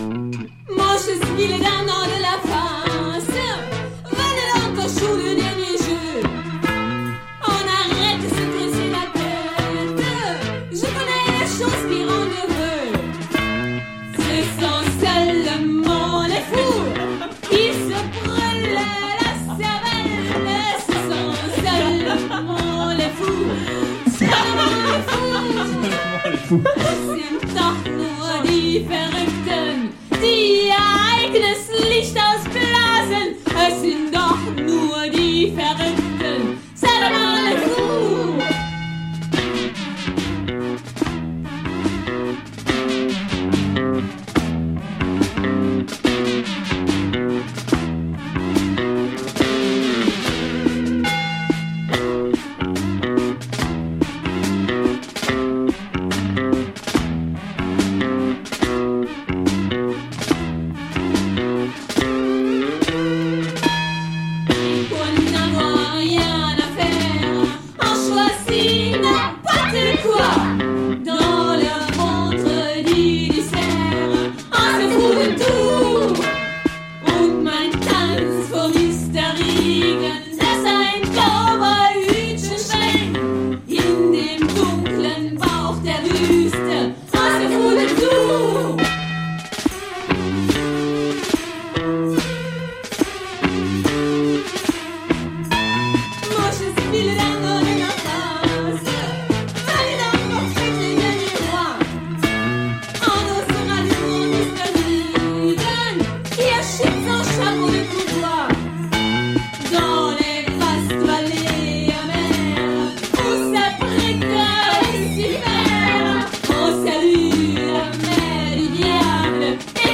Synth-pop / Art-rock